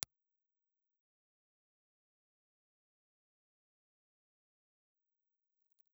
Impulse response recorded with the rear side of a C&T Naked Eye ribbon microphone
C&T_NakedEye_Rear_IR.wav
In my opinion the sound from the Roswellite is slightly darker than aluminium foil and the noise floor is a little higher.